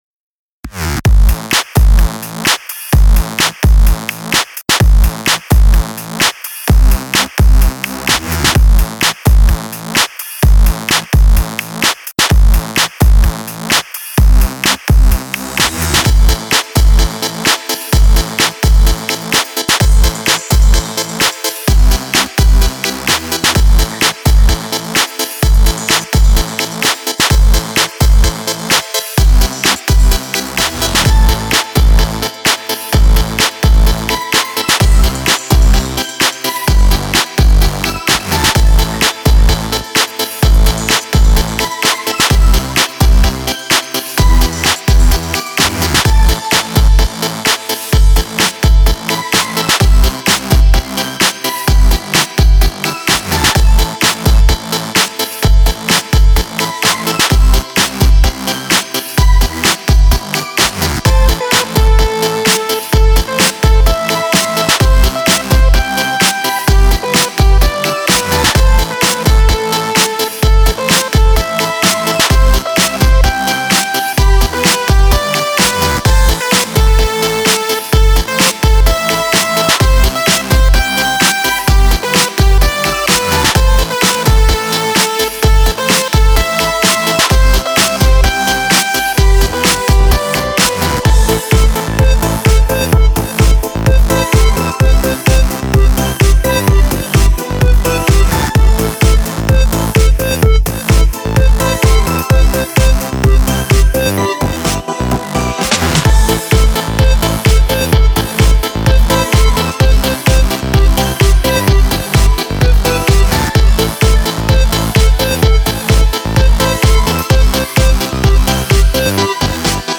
A rhythmic futuristic beat
electronic
instrumental
electro
positive
futuristic
feelgood
epic
drammatisch
ритмичная